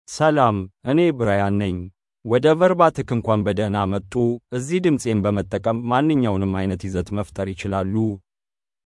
Brian — Male Amharic (Ethiopia) AI Voice | TTS, Voice Cloning & Video | Verbatik AI
Brian is a male AI voice for Amharic (Ethiopia).
Voice sample
Listen to Brian's male Amharic voice.
Male
Brian delivers clear pronunciation with authentic Ethiopia Amharic intonation, making your content sound professionally produced.